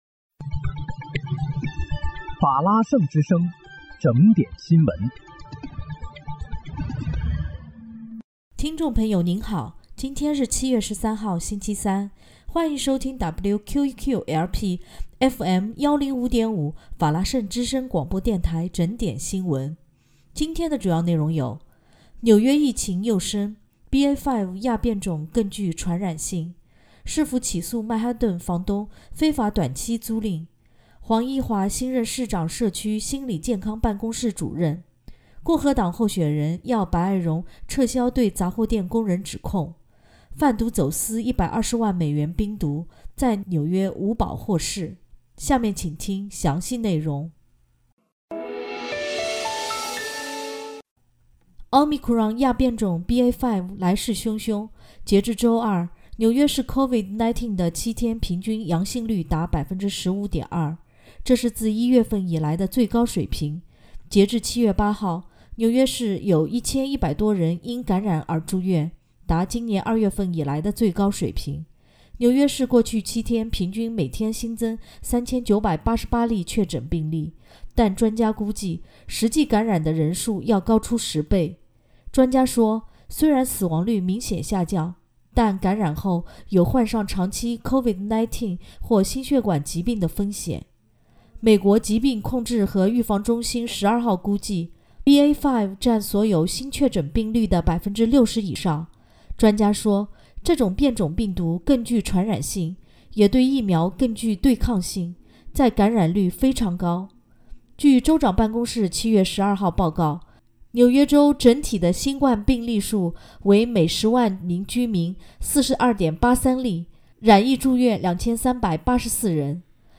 7月13日（星期三）纽约整点新闻